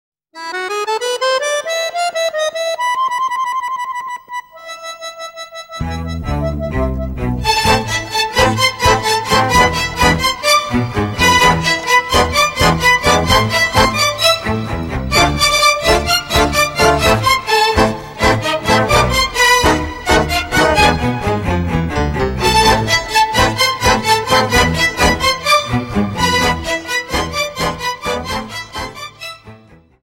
Tango 32 Song